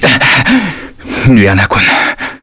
Fluss-Vox_Dead_kr.wav